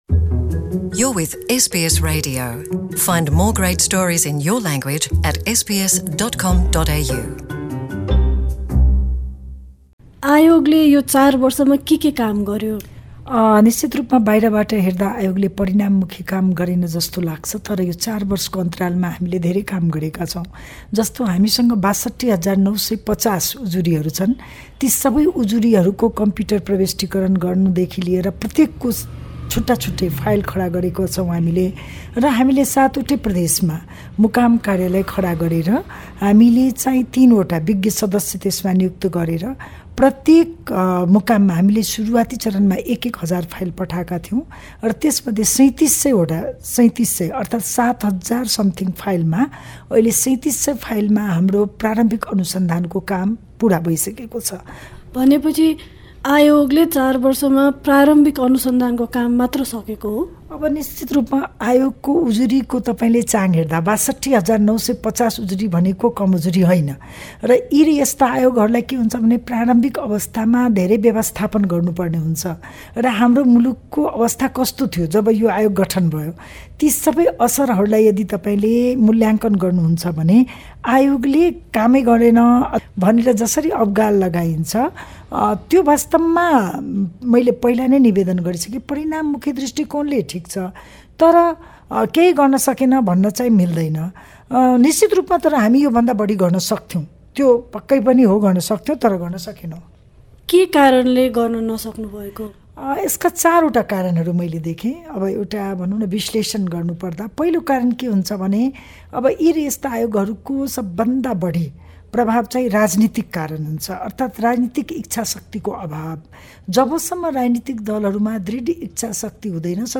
यही सिलसिलामा हामीले सत्य निरूपण तथा मेलमिलाप आयोगकी एकजना सदस्य मन्चला झासँग यी चार वर्षमा आयोगले गरेका कामबारे कुराकानी गरेका छौँ।
Manchala Kumari Jha, a member of Truth and Reconciliation Commission, Nepal speaks to SBS Nepali Source: SBS Nepali